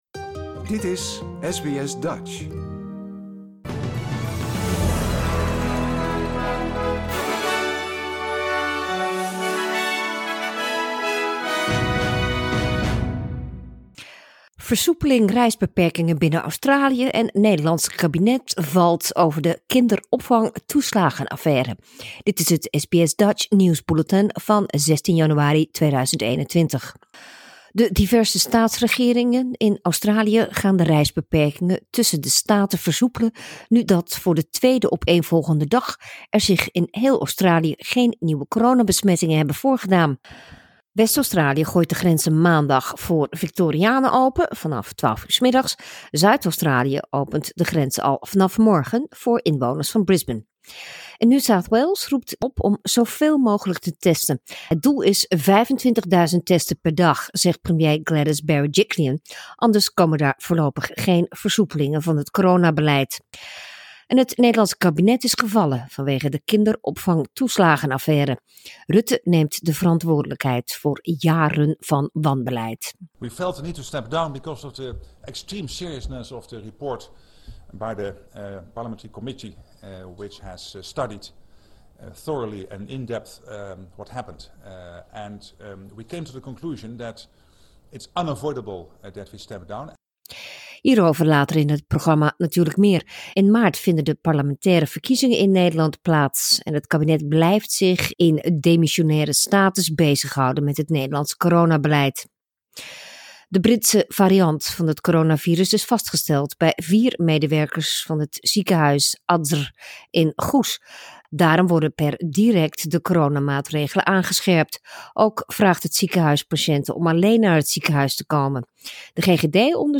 Nederlands/Australisch SBS Dutch nieuwsbulletin zaterdag 16 januari 2020